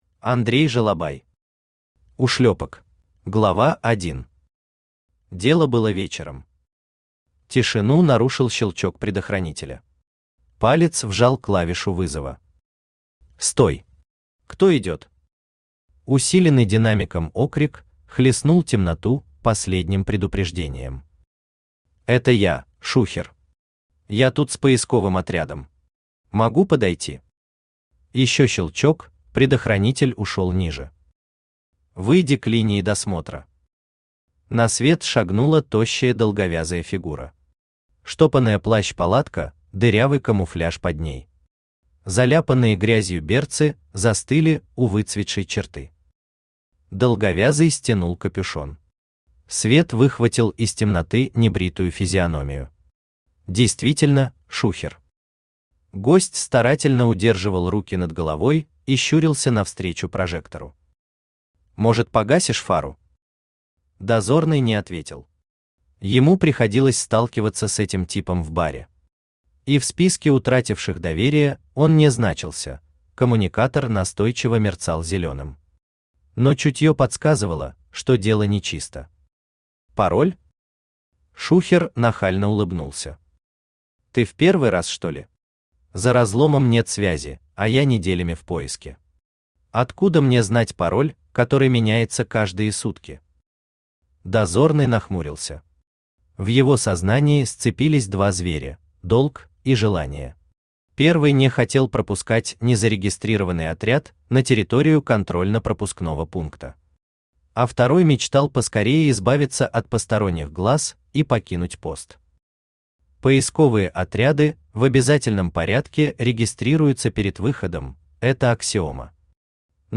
Аудиокнига Ушлёпок | Библиотека аудиокниг
Aудиокнига Ушлёпок Автор Андрей Васильевич Жолобай Читает аудиокнигу Авточтец ЛитРес.